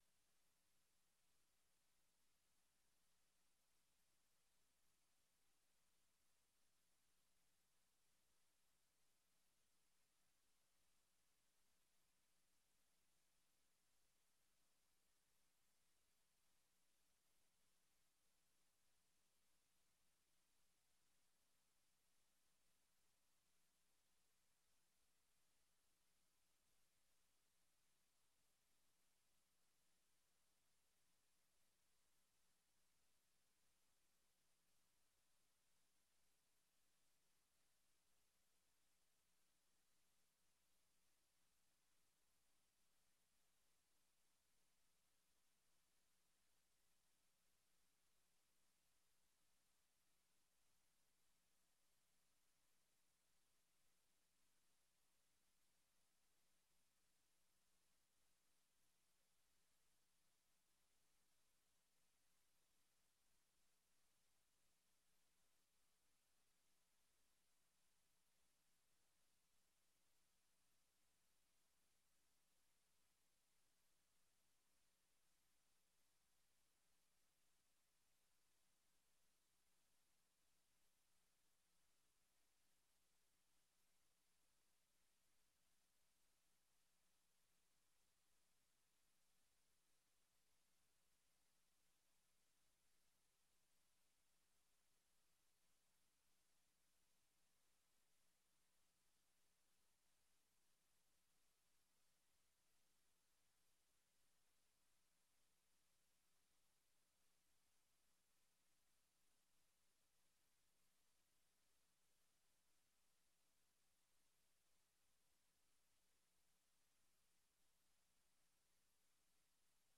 El gobierno cubano desató una oleada represiva tras las protestas del 11 de julio en Cuba. Madres de las víctimas y madres arrestadas en el contexto de las manifestaciones, dan testimonios de violaciones a los derechos humanos y judiciales en Cuba.
Entrevista